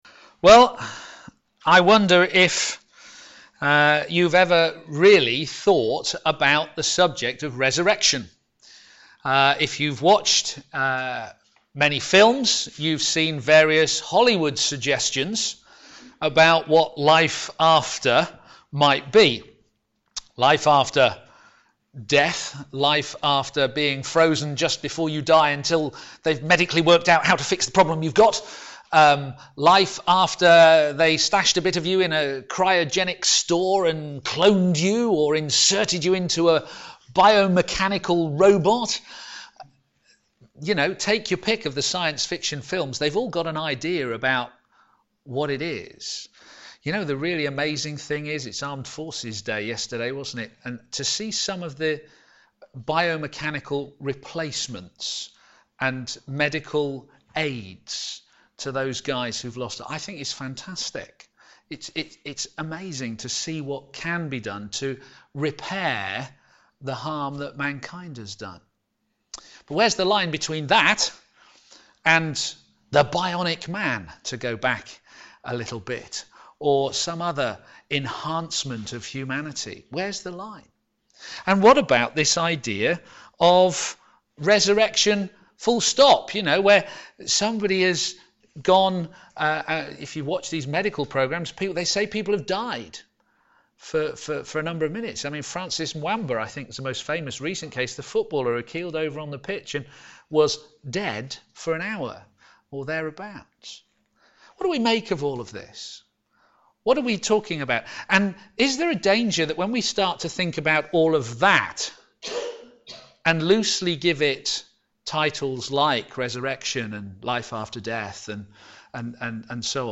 p.m. Service
Working Together to Advance the Gospel Theme: The Resurrection of Christ Sermon